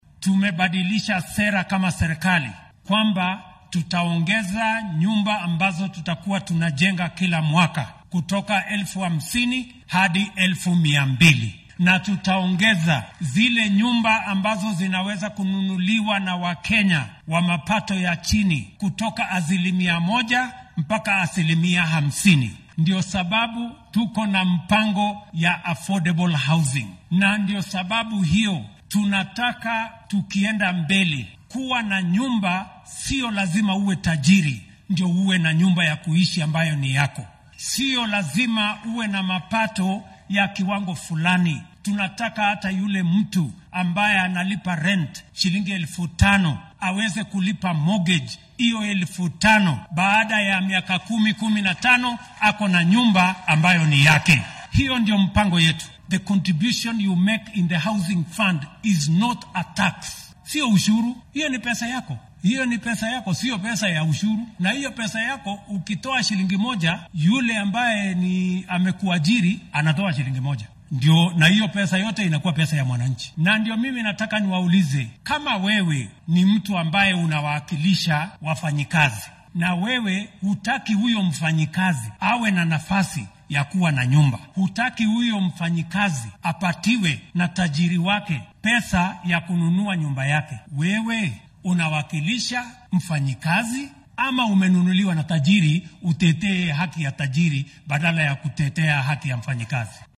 Hoggaamiyaha dalka William Ruto oo shalay xaafadda South C ee magaalada Nairobi ku daahfuray mashruuc dhanka guriyeynta ah ayaa hoggaamiyaasha siyaasadeed ka codsaday inaynan caqabad ku noqon qorshaha maamulkiisa ee dhanka hooyga ah.